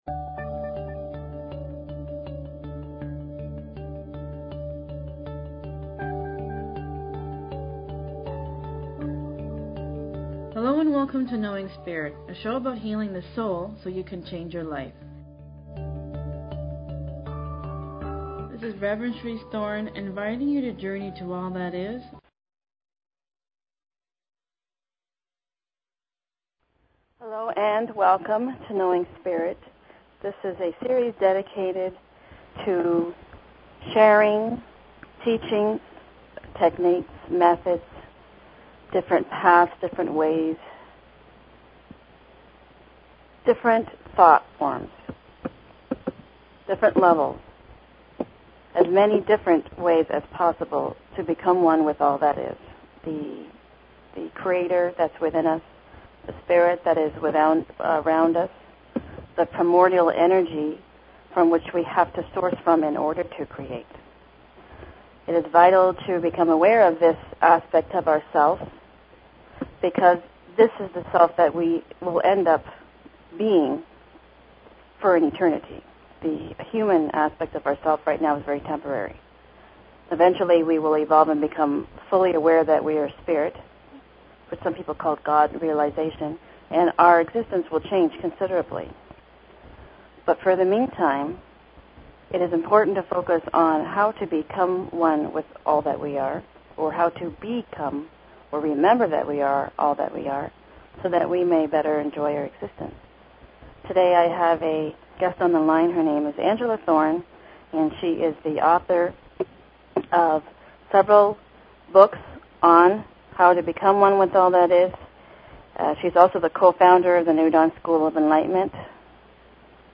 Talk Show Episode, Audio Podcast, Knowing_Spirit and Courtesy of BBS Radio on , show guests , about , categorized as